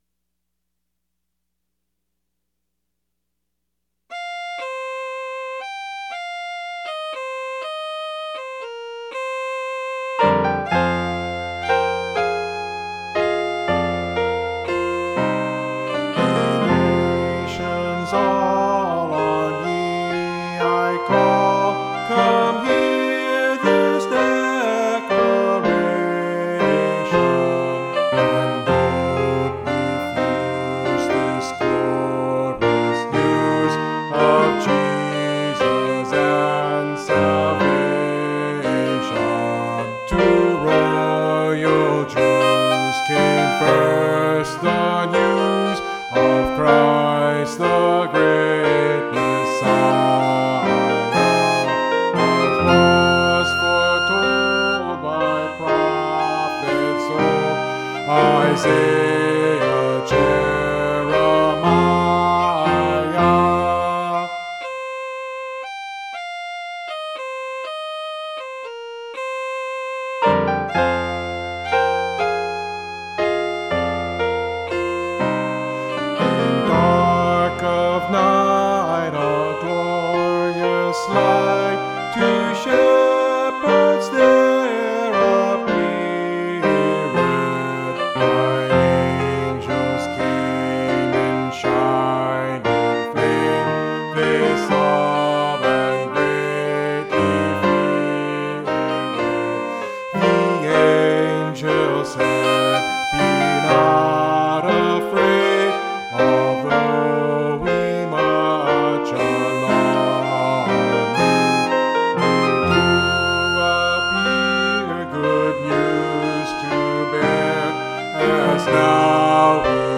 Mountain Christmas Carol
Alto